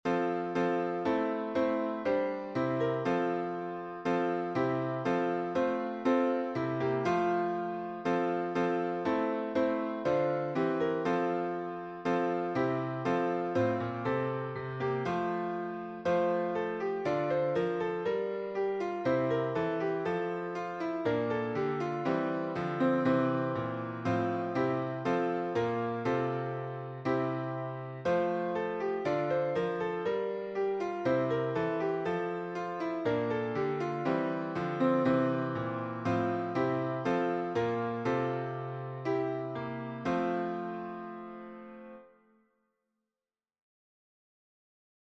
French carol